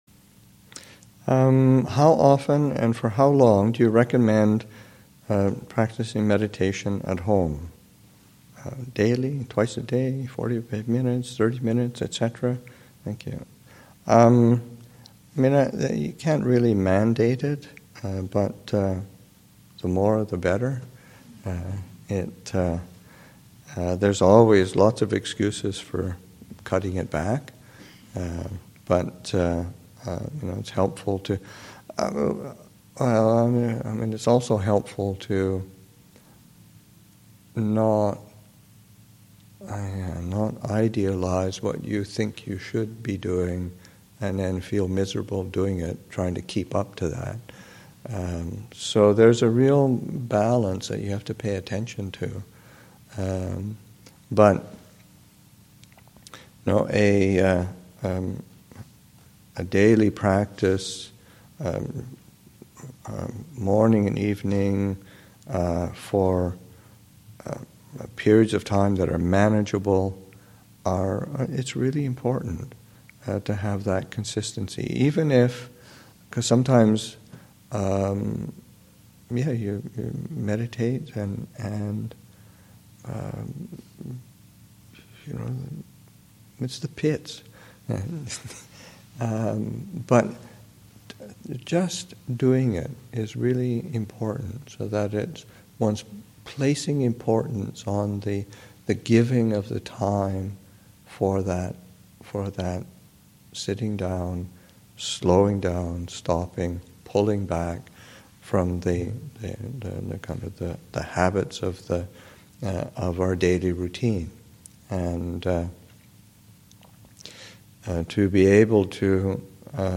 2014 Thanksgiving Monastic Retreat, Session 2 – Nov. 23, 2014